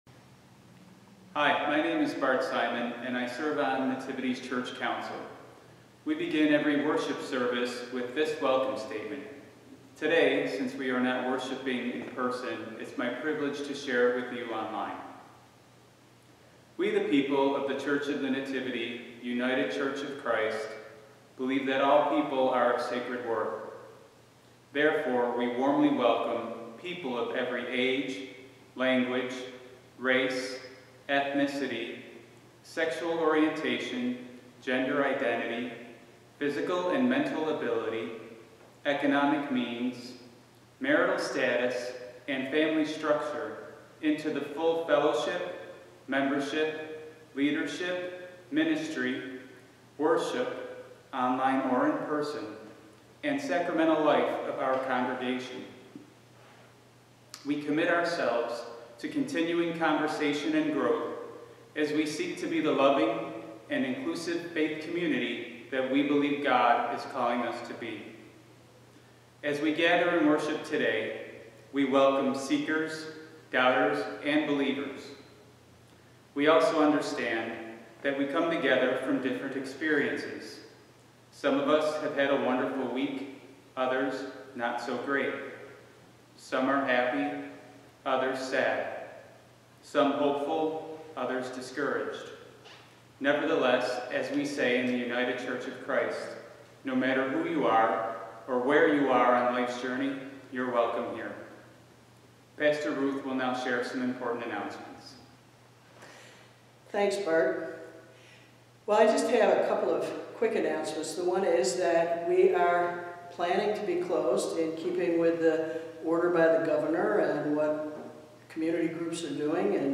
Service for 3-22-20
Sermon or written equivalent